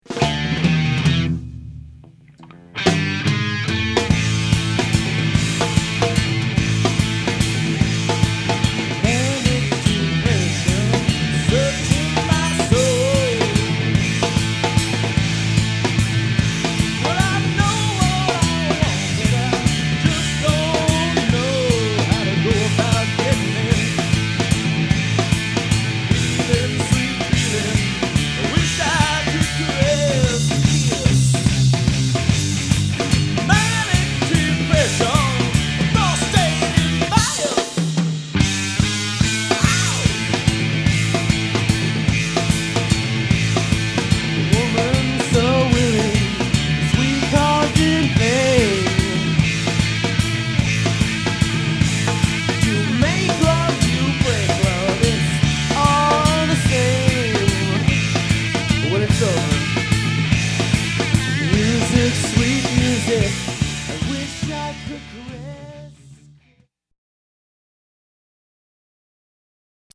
Cover Songs